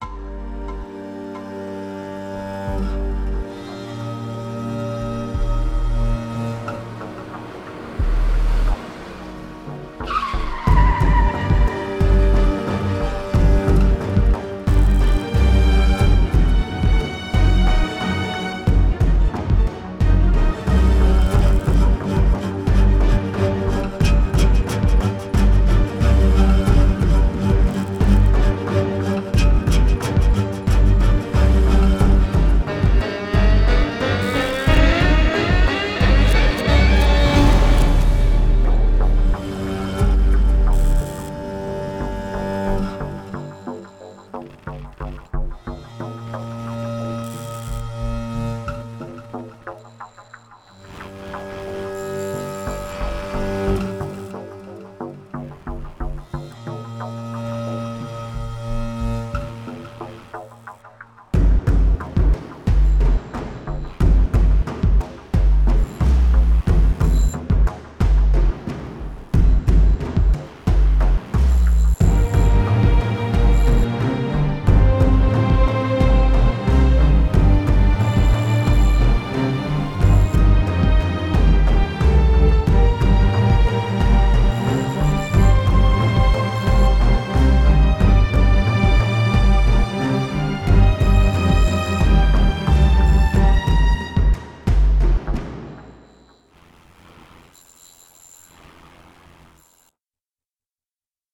Le délai sur chaque instrument quasi permanent accentue bien le côté millimétré de l'action!!!
Le début sonne bien, on sent vraiment beaucoup la tension.
Dommage, que ça se répete un peu trop sur la durée.